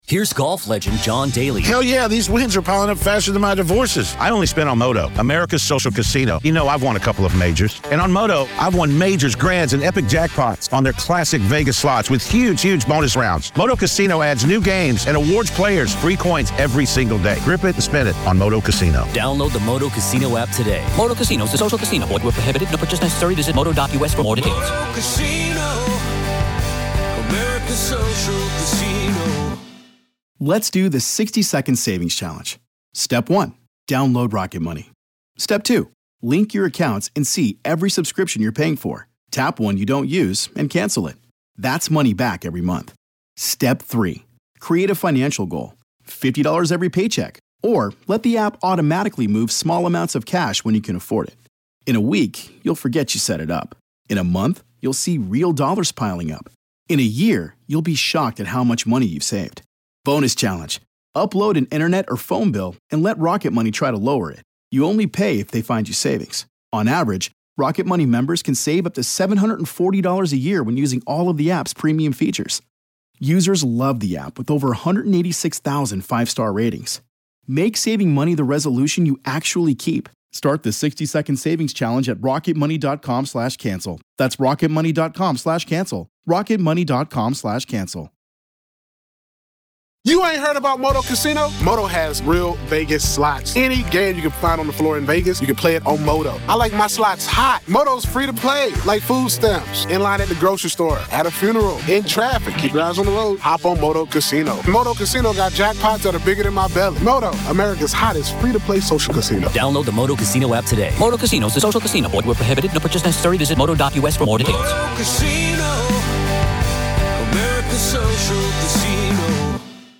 Your calls and stories of real hauntings.